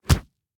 punch13.ogg